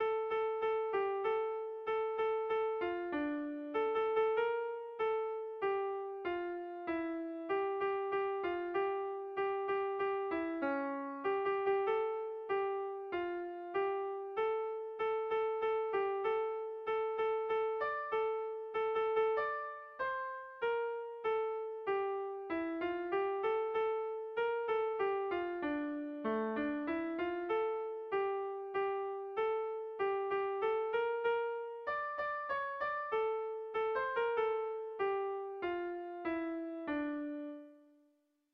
Kontakizunezkoa
Hamarreko handia (hg) / Bost puntuko handia (ip)
ABDEF